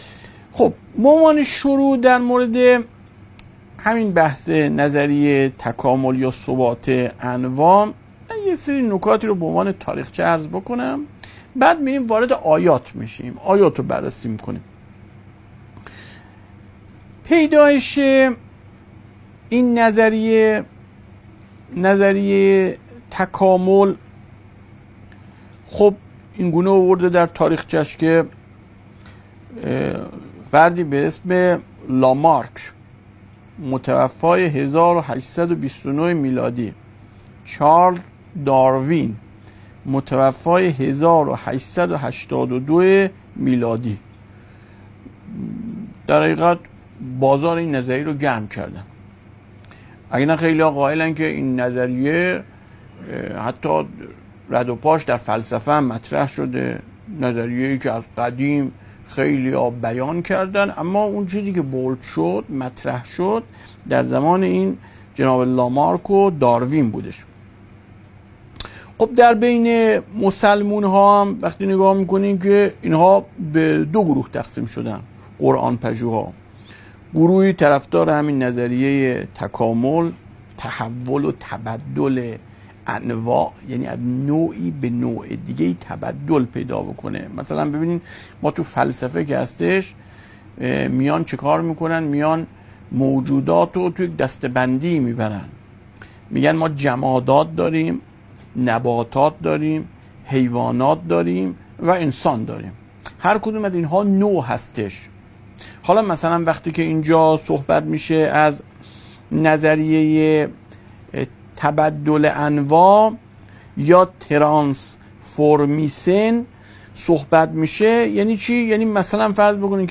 صوت ســـخنرانی: